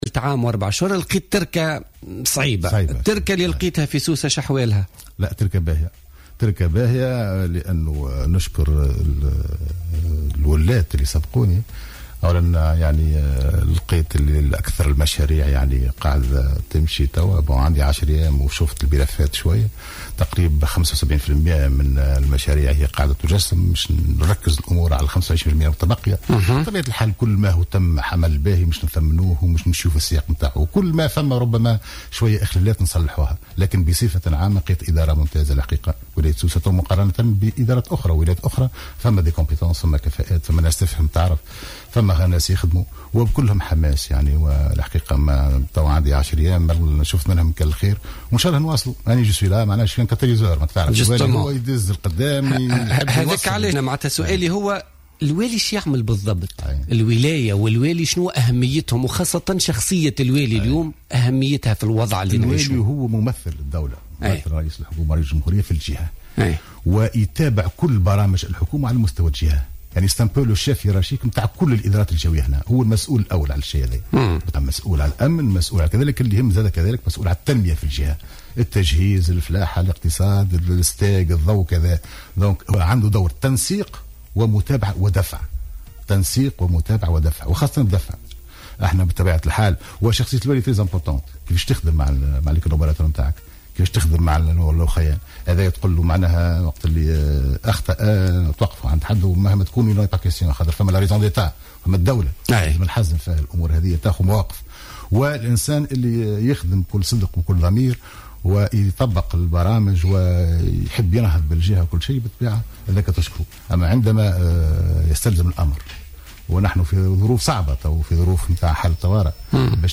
أكد والي سوسة فتحي بديرة ضيف بوليتيكا اليوم الخميس 16 جويلية 2015 أنه وجد تركة جيدة في سوسة و ادارة ممتازة للملفات المطروحة في الولاية على خلاف التركة الثقيلة التي وجدها حين تم تنصبه واليا على زغوان.